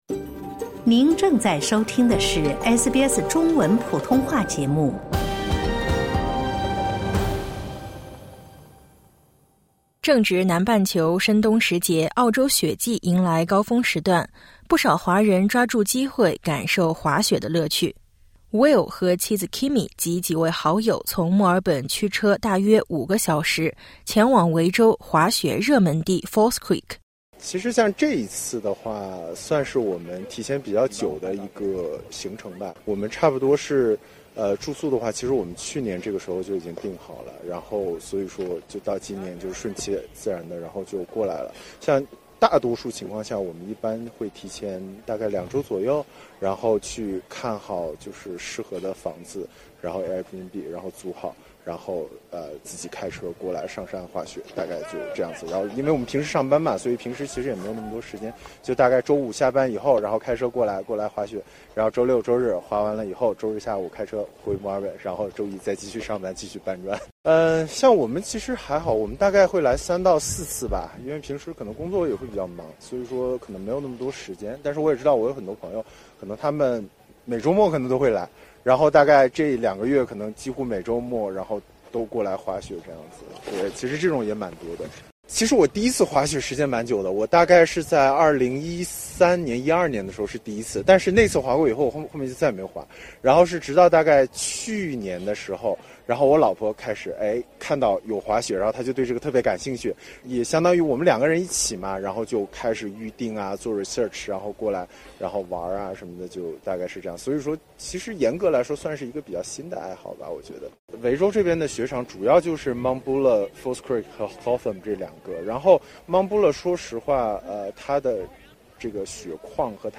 点击音频，收听完整报道。